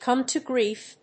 còme to gríef
発音